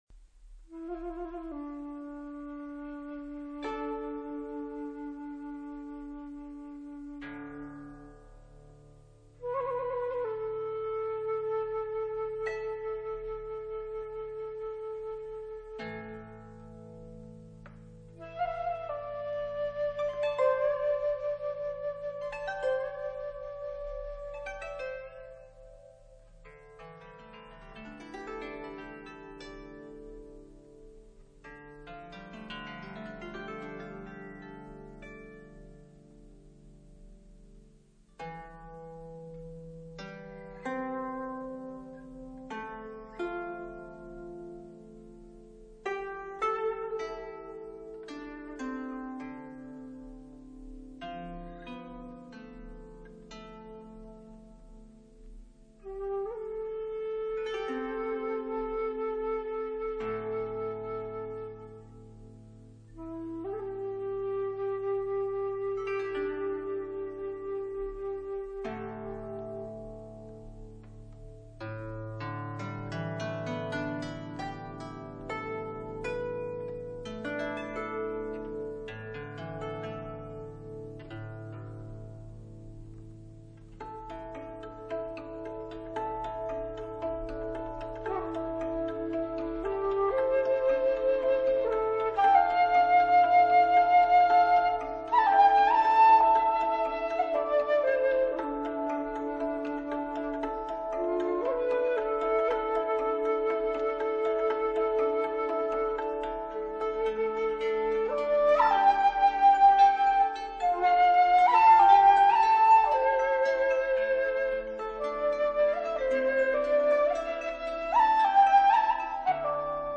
缥渺的山水画的意境 带着你进入一个空灵